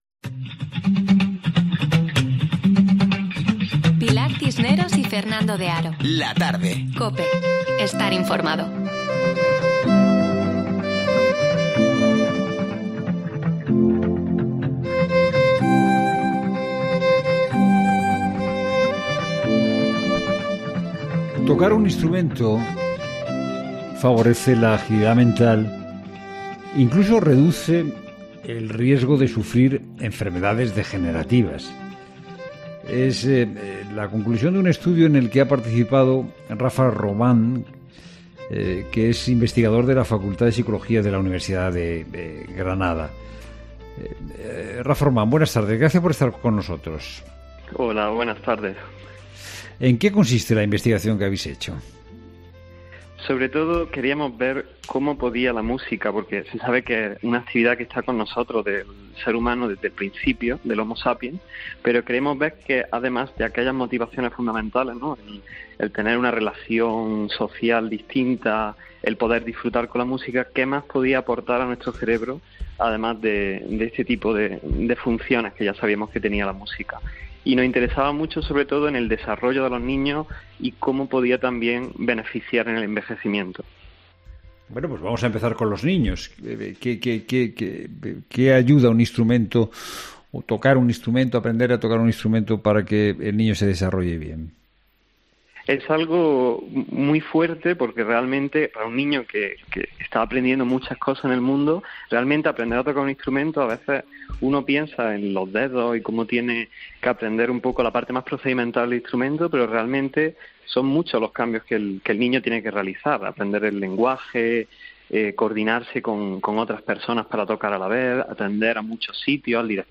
Durante la emisión del programa de ‘La Tarde’